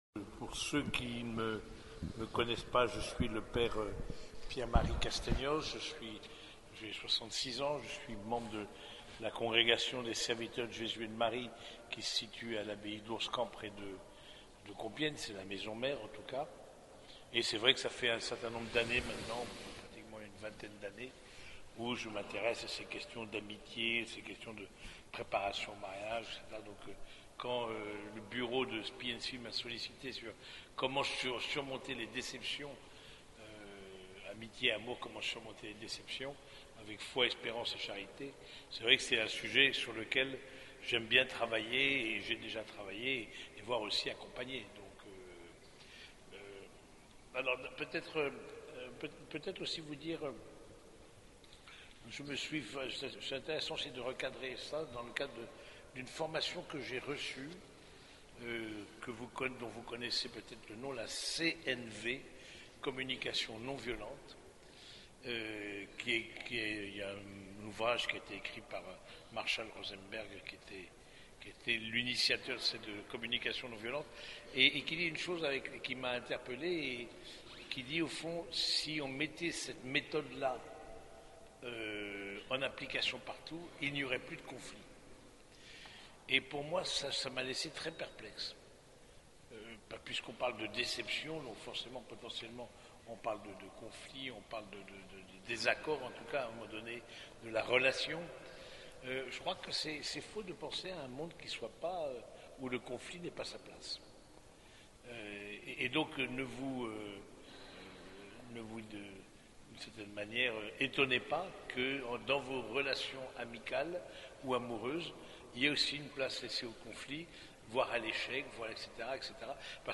Conférence Spi&Spi d’avril 2026